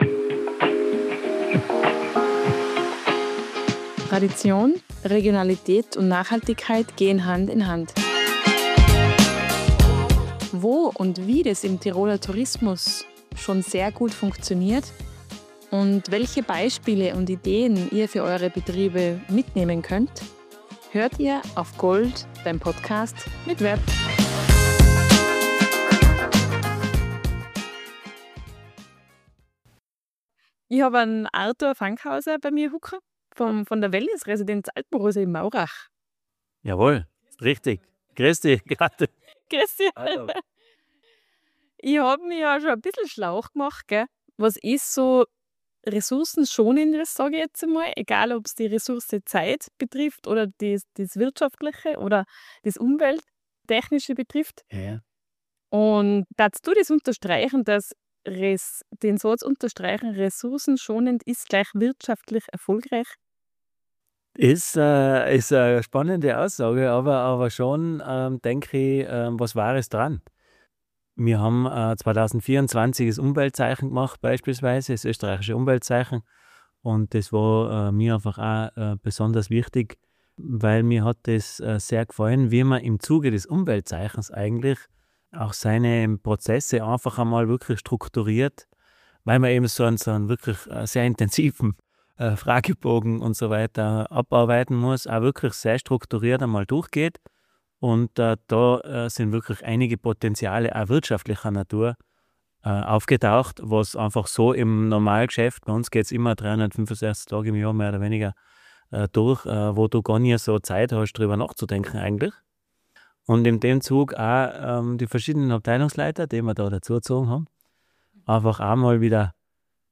Tiroler Hoteliers